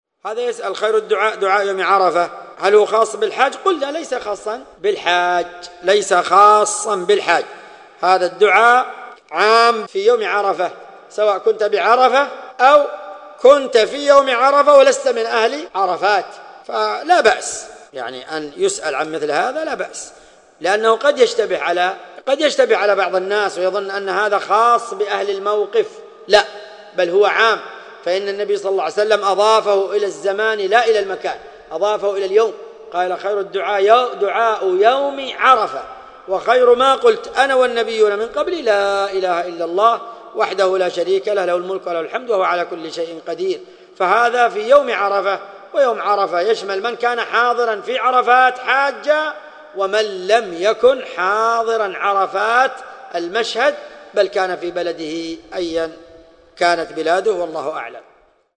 السؤال من محاضرة بعنوان : ( فضل يوم عرفة وأيام التشريق ).
ملف الفتوي الصوتي عدد الملفات المرفوعه : 1